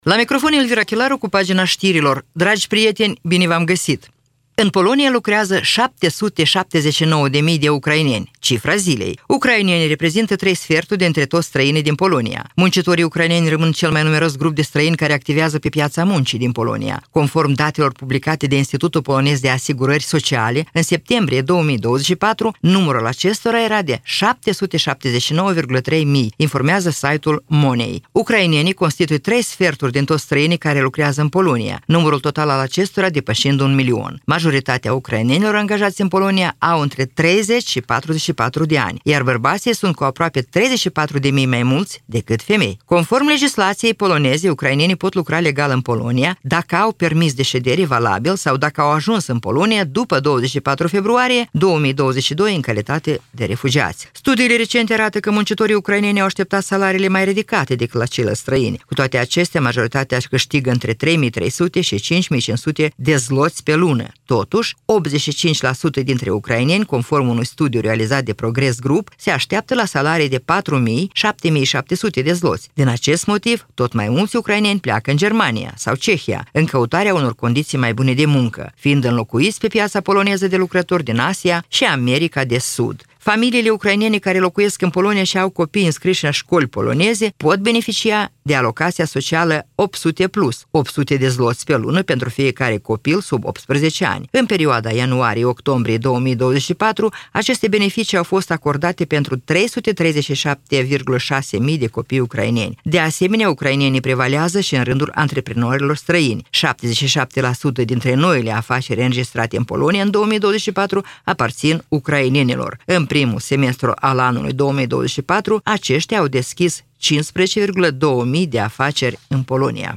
Știri Radio Ujgorod – 20.11.2024